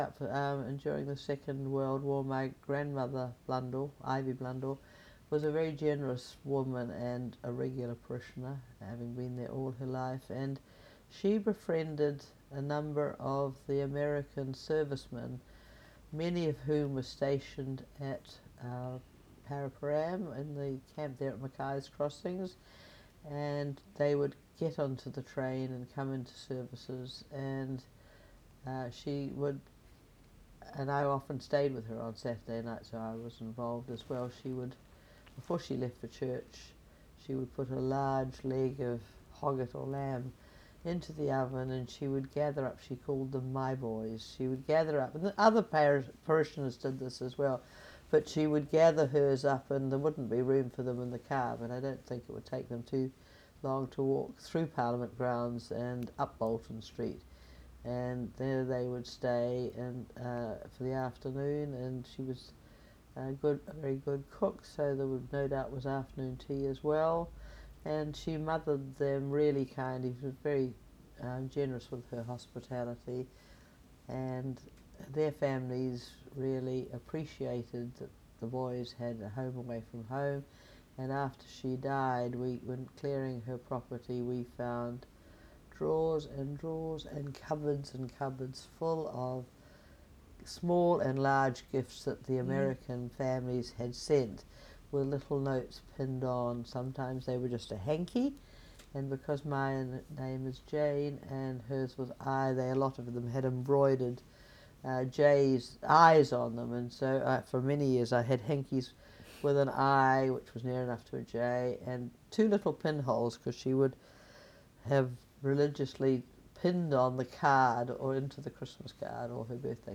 herself a long-term supporter of Old St Paul’s. In this oral history interview